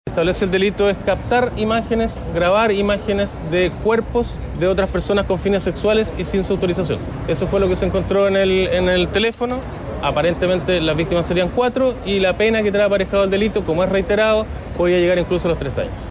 Por su parte, Álvaro Mansilla, fiscal de Valparaíso, en el momento de la formalización del juez Arancibia, describió los delitos y adelantó que podrían ser hasta tres años de cárcel.